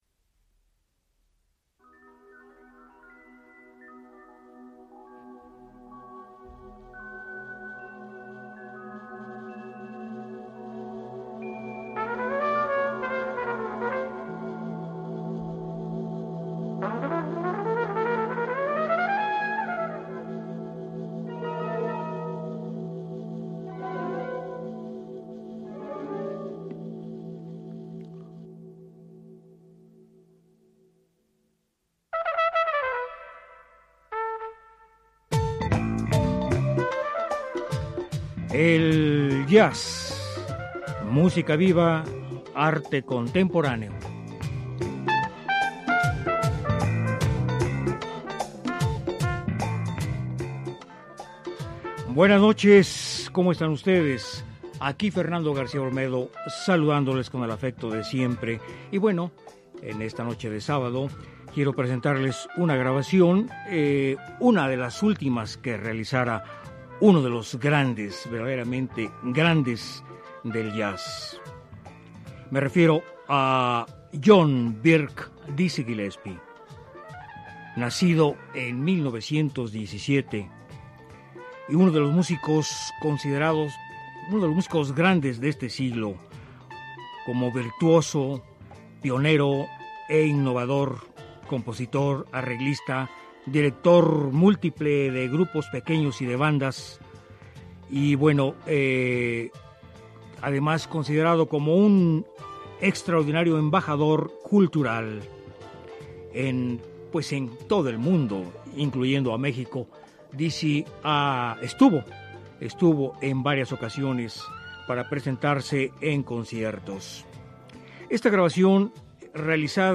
trompetista, cantante y compositor estadounidense de jazz